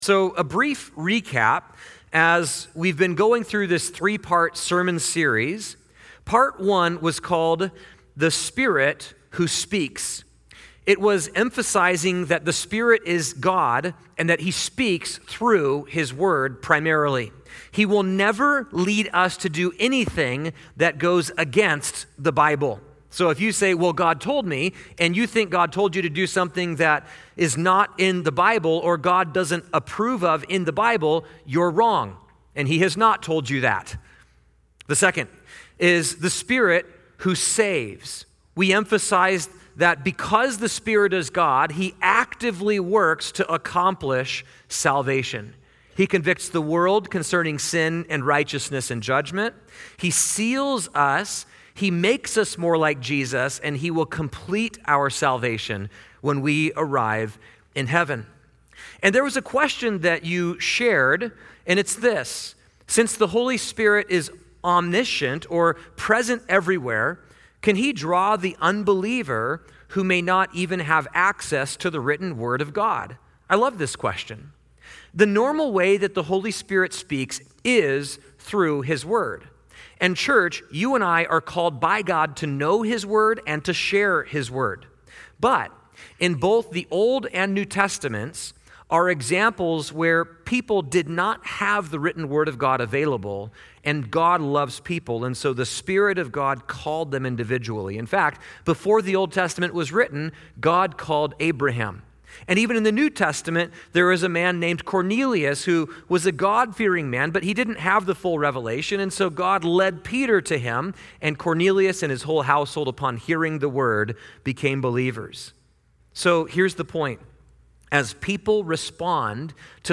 Closing prayer and altar call